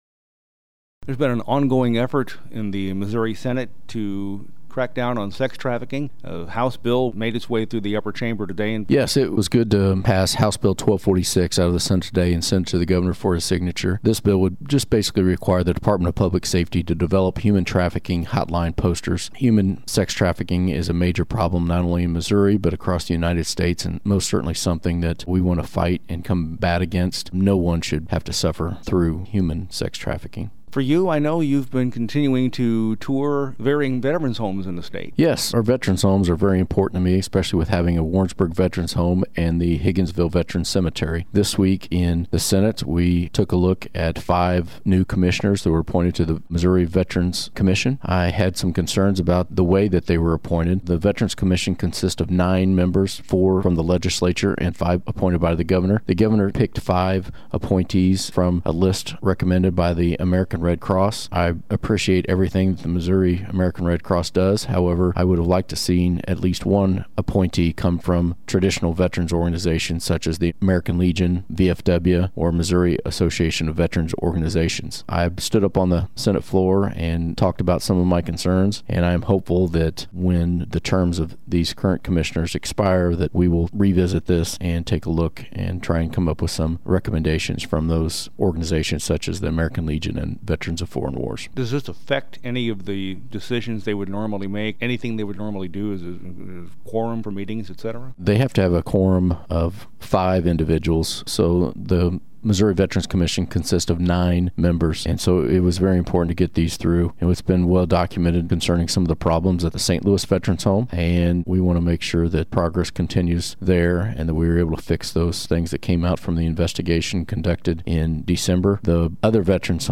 JEFFERSON CITY — State Sen. Denny Hoskins, R-Warrensburg, discusses House Bill 1246, which would require certain locations and businesses to post information regarding human trafficking.